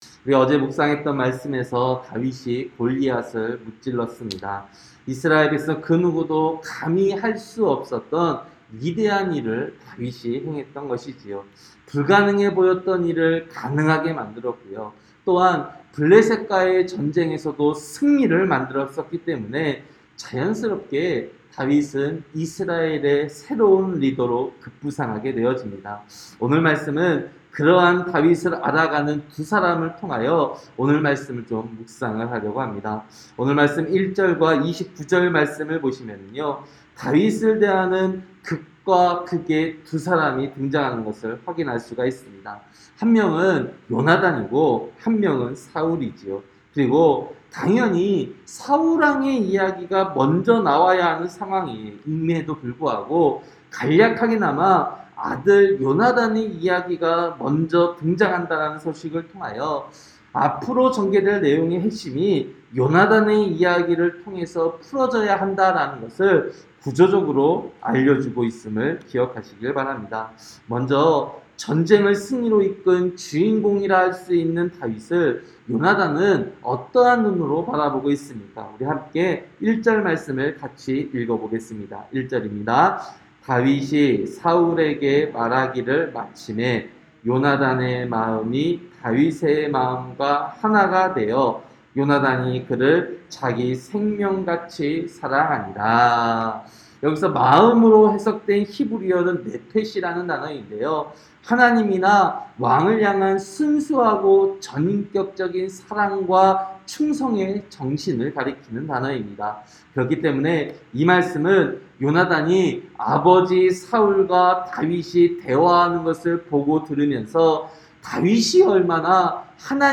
새벽설교-사무엘상 18장